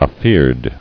[a·feard]